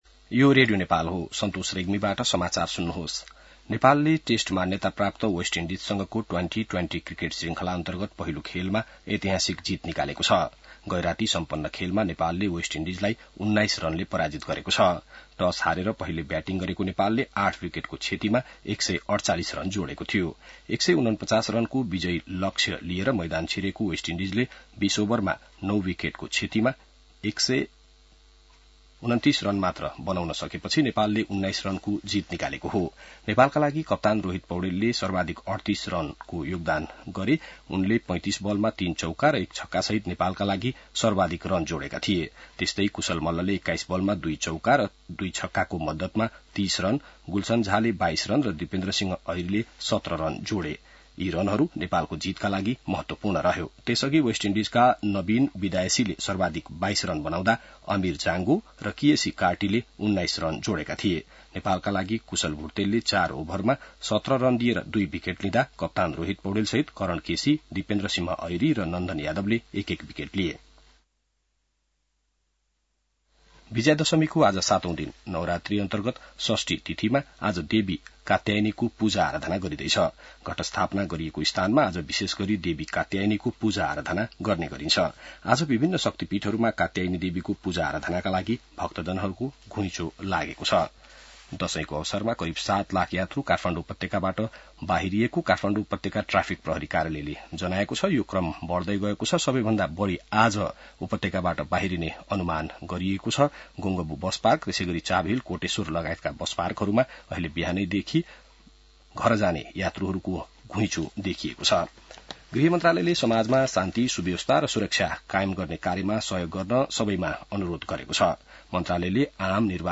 बिहान ६ बजेको नेपाली समाचार : १२ असोज , २०८२